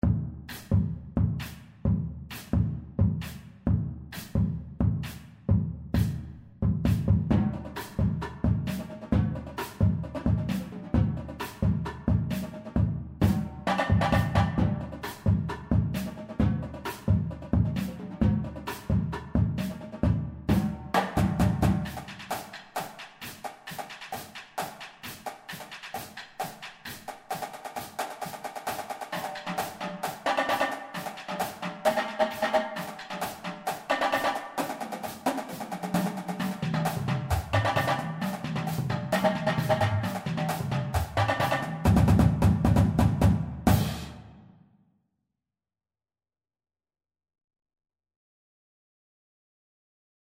Voicing: Percussion Cadence